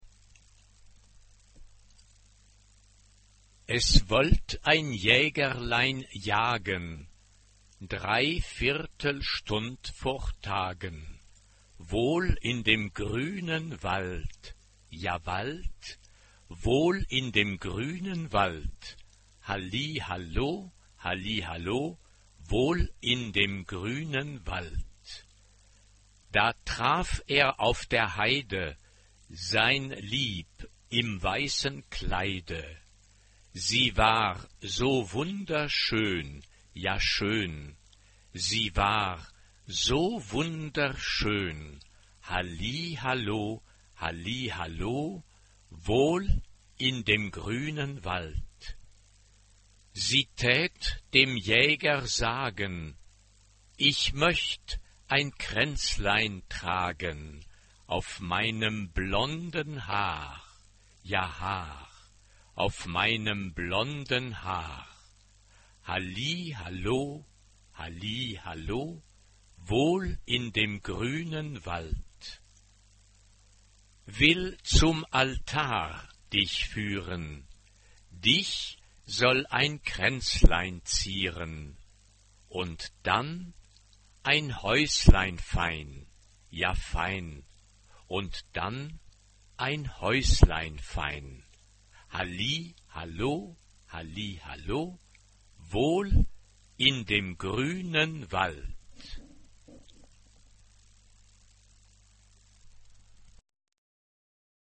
SATB (4 voix mixtes) ; Partition complète.
Folklore
Trompette
Tonalité : do majeur